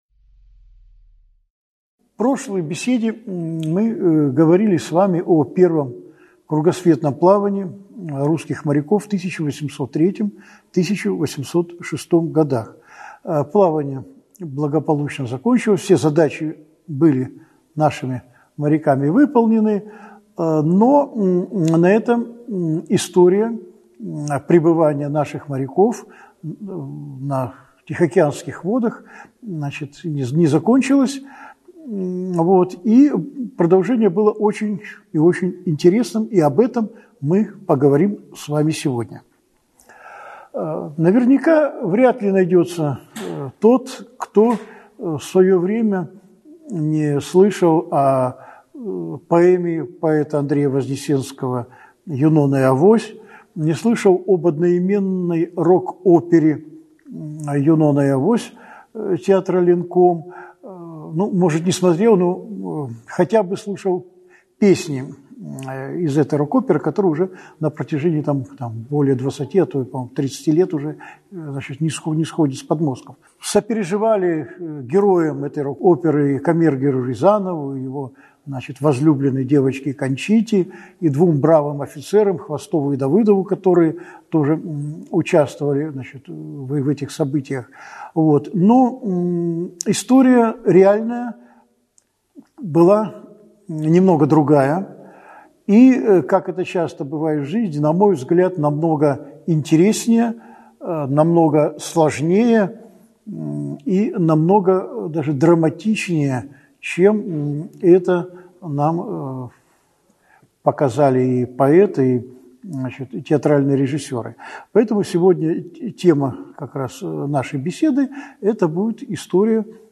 Аудиокнига «Юнона» и «Авось»: правда и мифы | Библиотека аудиокниг